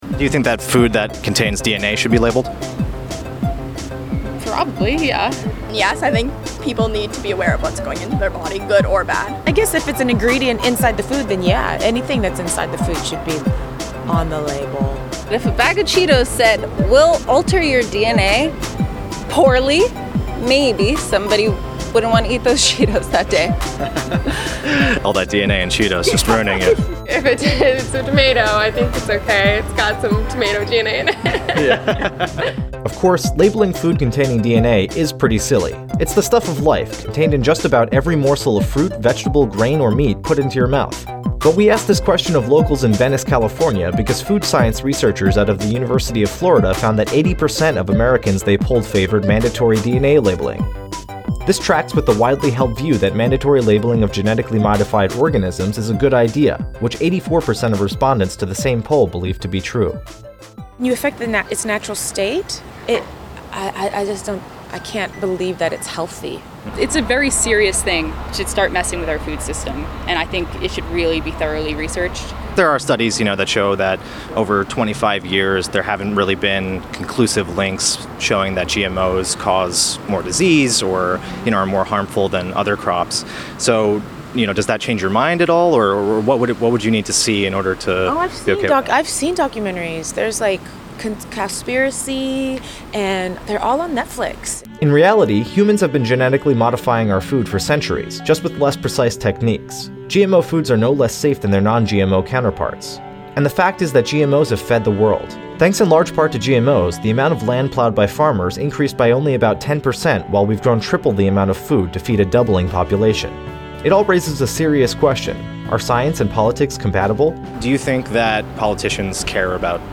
Reason TV asked locals in Venice, California about their thoughts on various scientific policy questions and compared their answers to public opinion poll data.